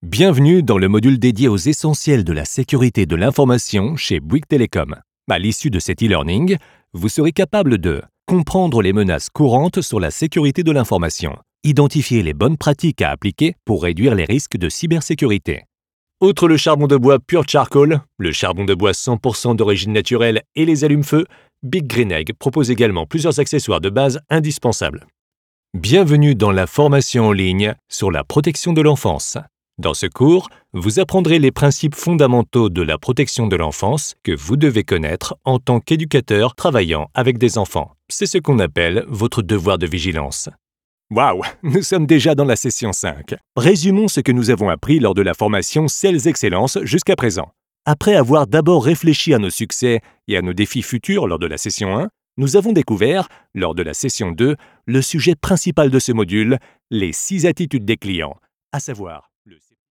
Zugänglich, Vielseitig, Zuverlässig, Warm
E-learning